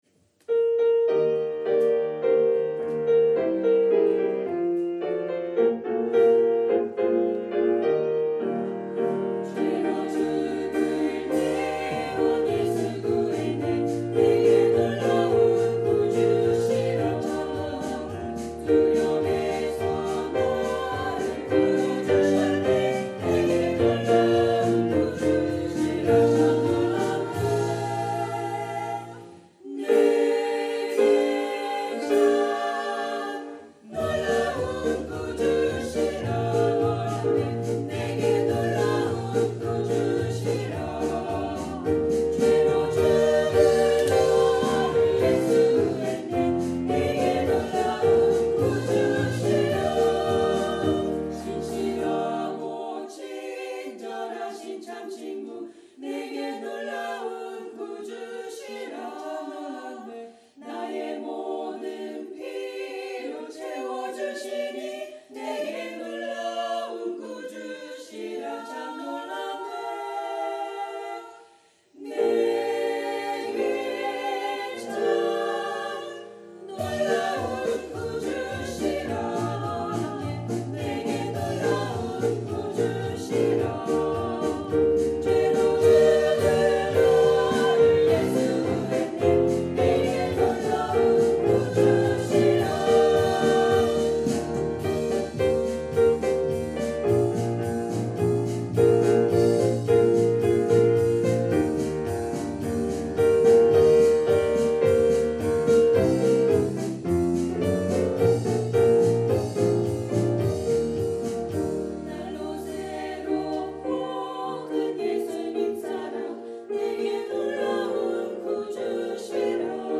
psalmchoir